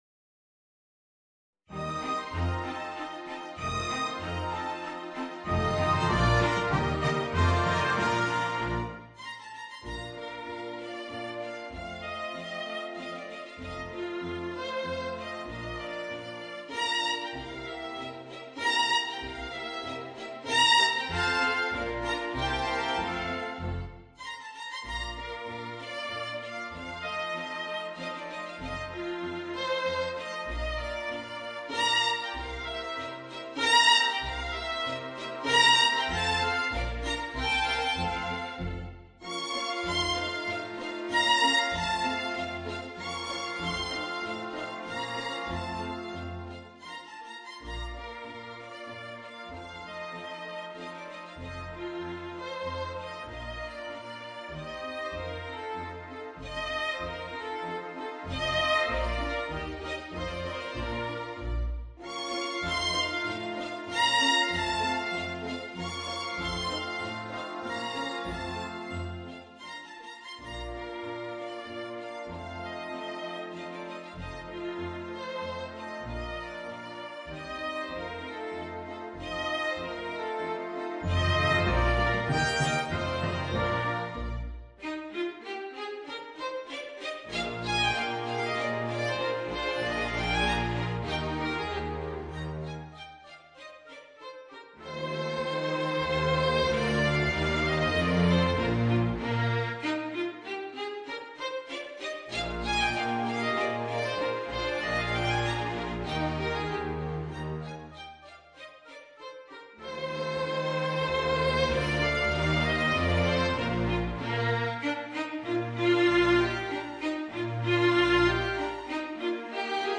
Voicing: Piccolo and Orchestra